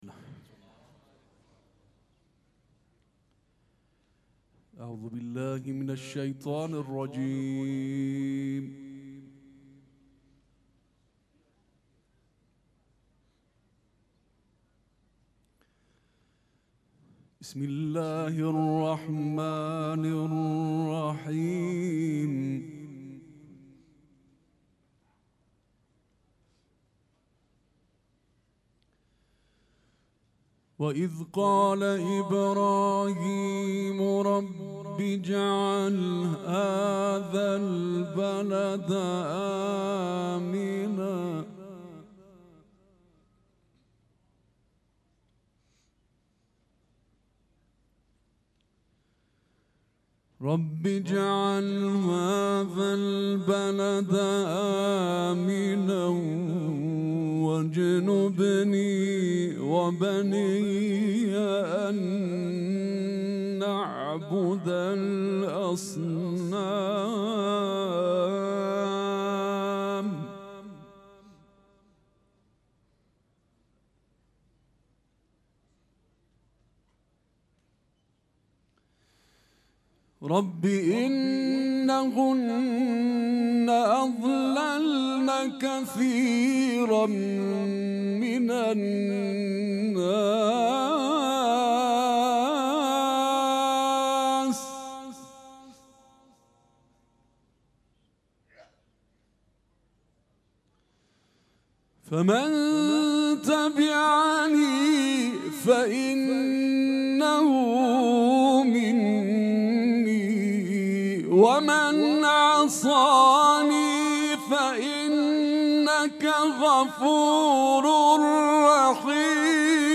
مراسم عزاداری شب هشتم محرم الحرام ۱۴۴۷
قرائت قرآن